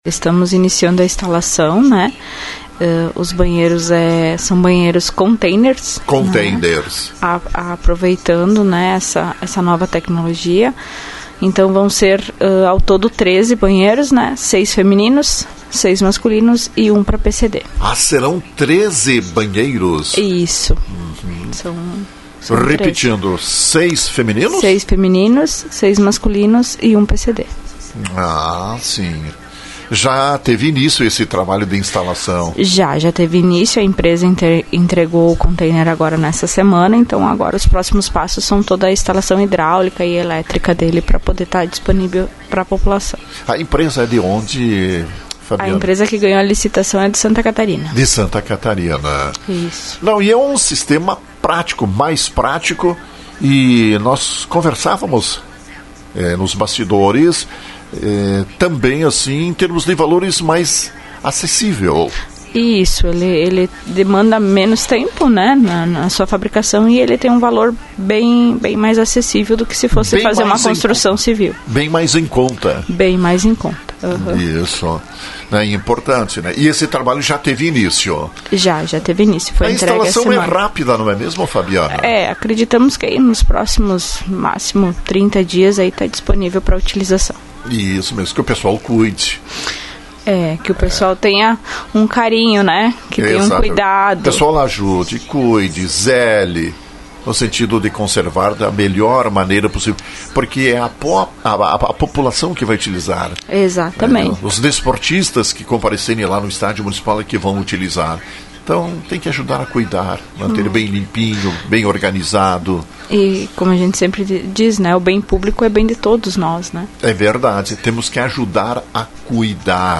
Estão sendo instalados banheiros no Estádio Municipal Adão Castellano de Lagoa Vermelha, destinados ao público presente por ocasião de jogos. Ao todo, são 9 banheiros. Secretária Municipal do Planejamento, Fabiana Prestes, foi quem transmitiu a informação.